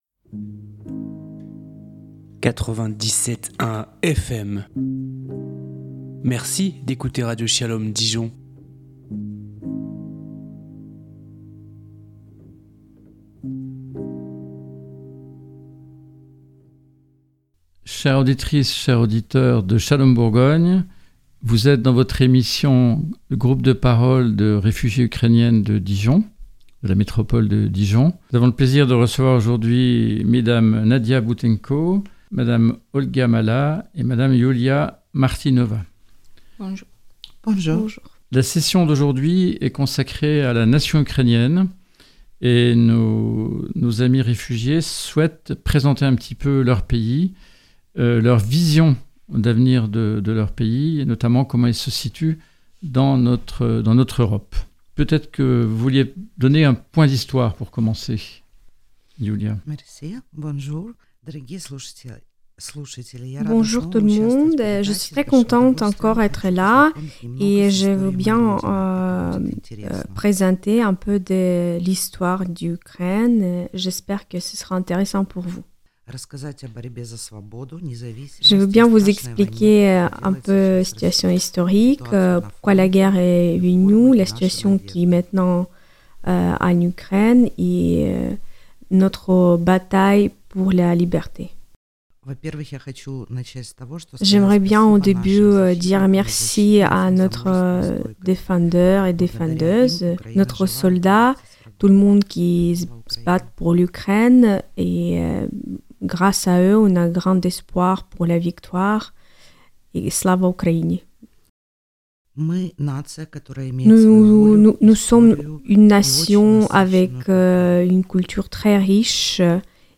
Le 19 mars 2024, l'atelier Groupe des paroles de personnes réfugiées ukrainiennes s'est réuni de nouveau dans le studio de "Shalom Bourgogne".Les échanges ont eu lieu autour de la question de la nation ukrainienne et de son projetdémocratique et européen.